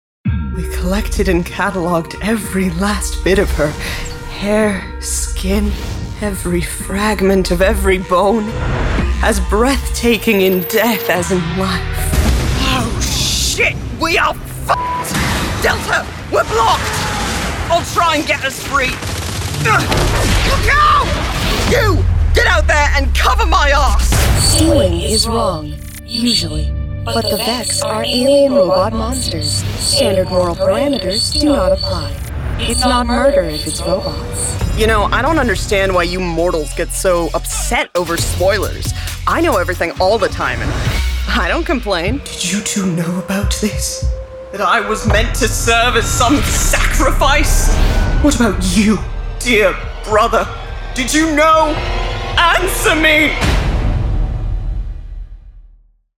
Videogame Demo